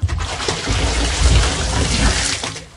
techage_scoopwater.ogg